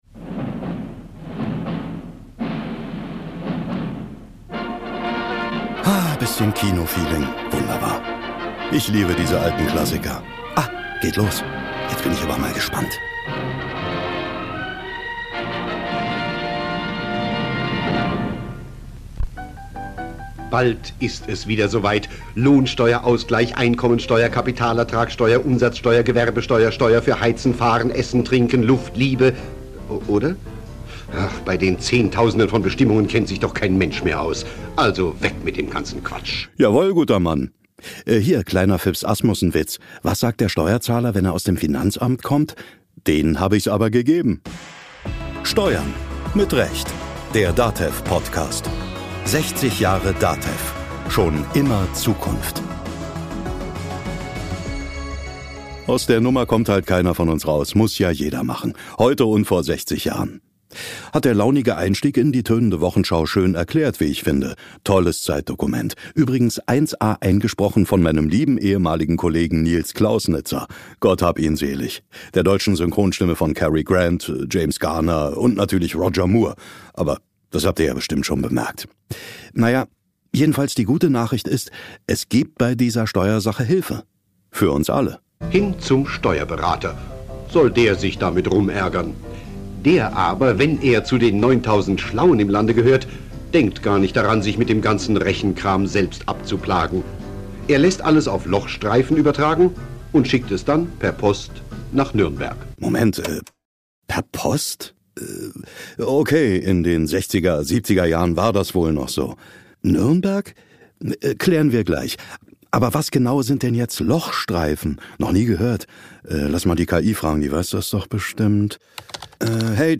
Mit Originaltönen, etwas Zeitkolorit und der Frage: Warum ausgerechnet eine Genossenschaft das richtige Modell für die digitale Revolution der steuerberatenden Berufe war und ist?